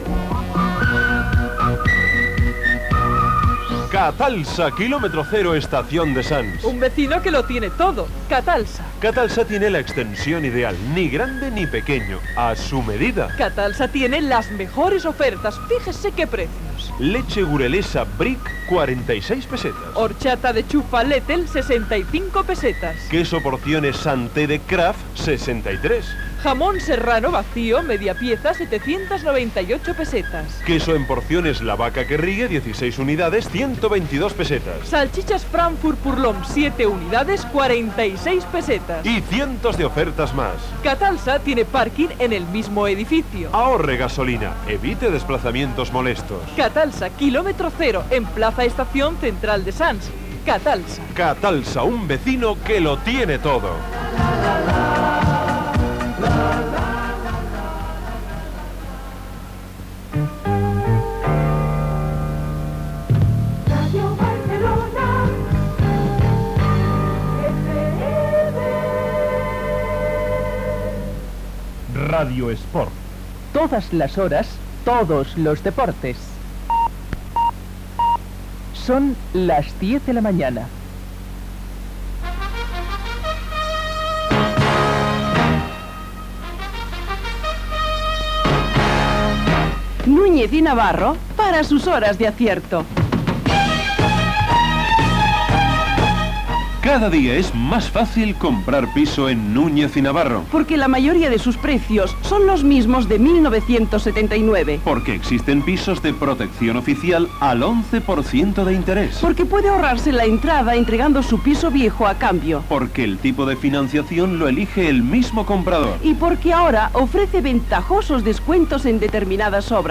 Publicitat, indicatius de l'emissora i del programa, hora, publicitat (veu Jordi Hurtado), indicatiu del programa. Informatiu esportiu on es parla del Torneig Internacional Universitari de futbol, de partits internacionals amistosos, del partit entre Mappen i FC Barcelona on marca Maradona, Waterpolo, etc.
Indicatiu de sortida del programa i indicatiu de l'emissora. Presentació d'un tema musical i salutació del locutor. Més temes musicals.
Info-entreteniment